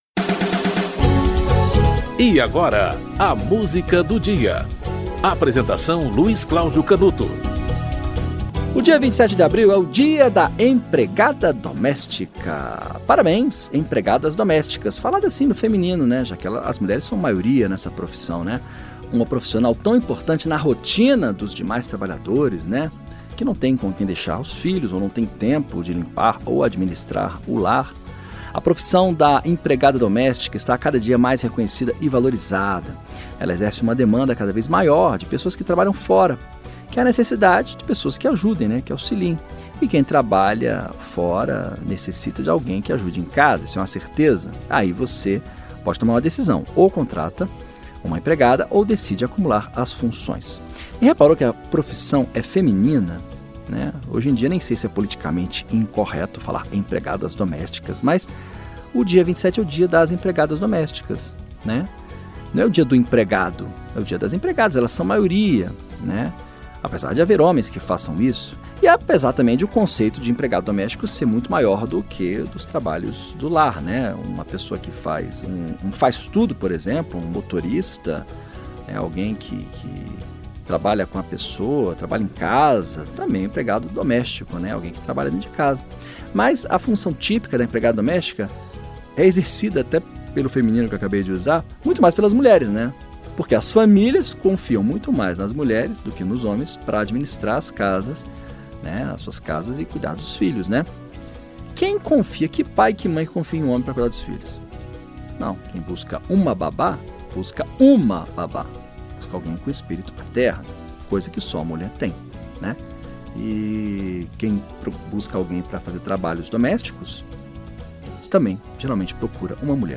Eduardo Dussek - Brega Chique (Eduardo Dussek e Luís Carlos Góes)
O programa apresenta, diariamente, uma música para "ilustrar" um fato histórico ou curioso que ocorreu naquele dia ao longo da História.